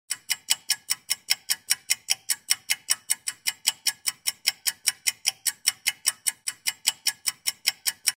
دانلود صدای تیک تاک ساعت 3 از ساعد نیوز با لینک مستقیم و کیفیت بالا
جلوه های صوتی